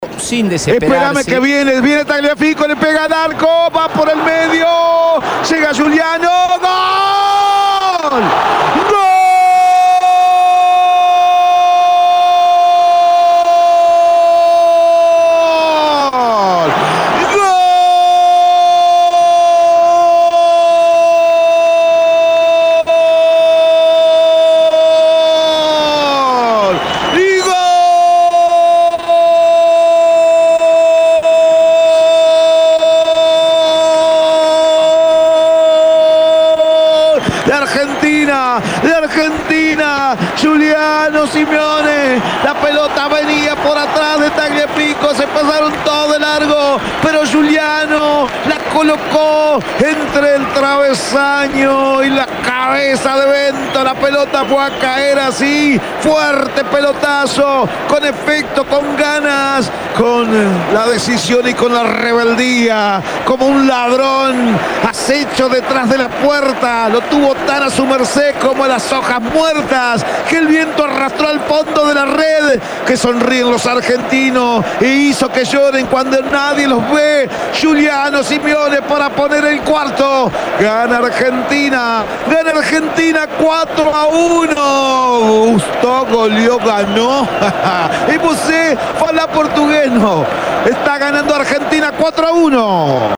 EN EL RELATO